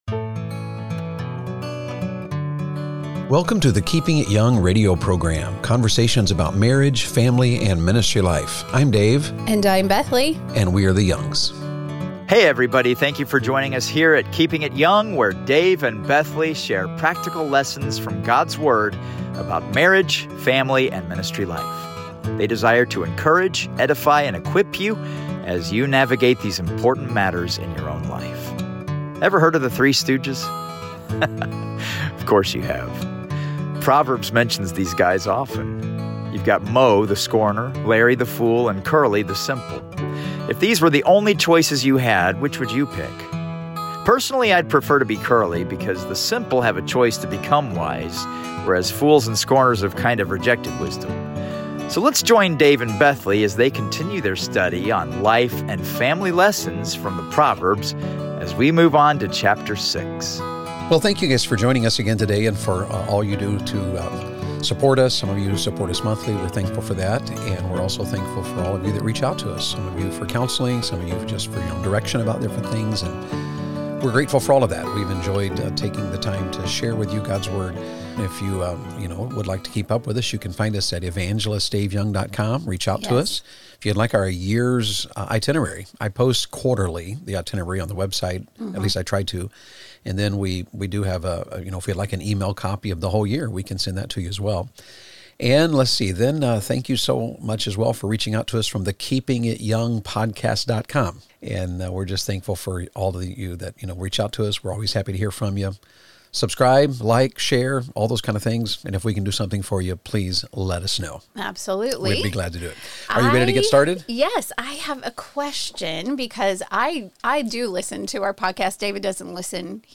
Archived Keeping it Young Radio Broadcast from April 2023